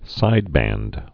(sīdbănd)